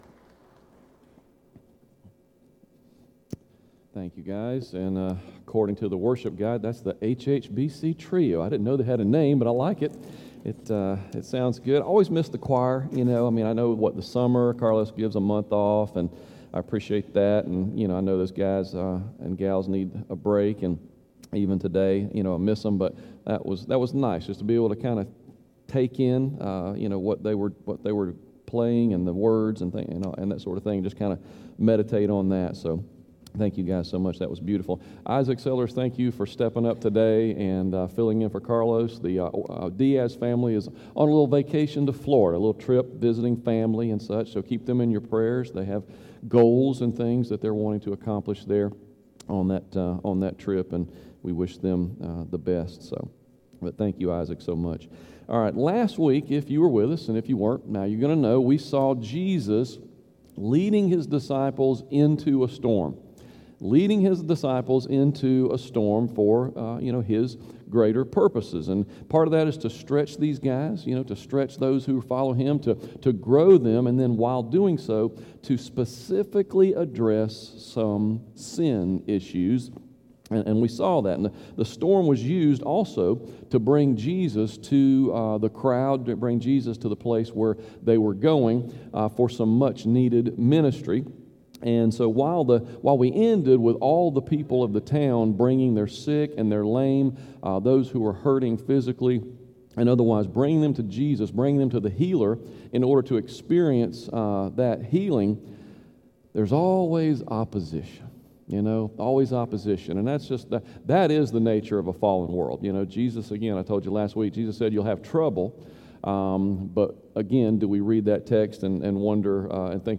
Sermons | Hampton Heights Baptist Church